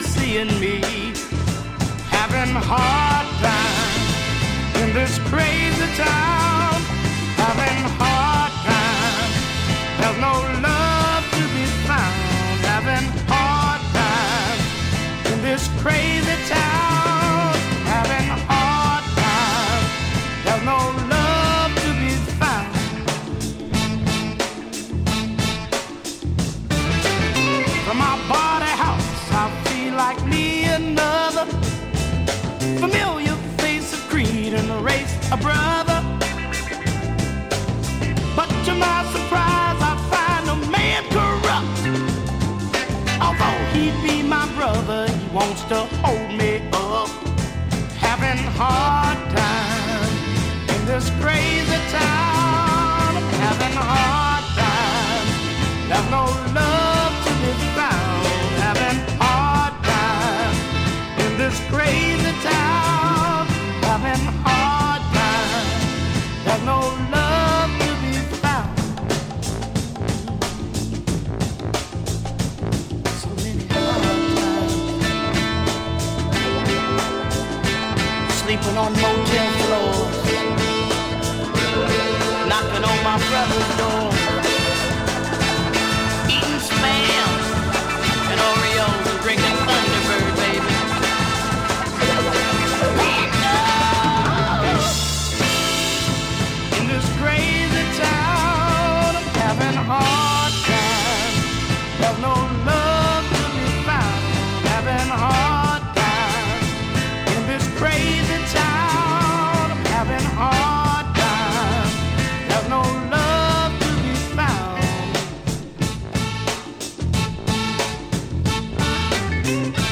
Soul Funk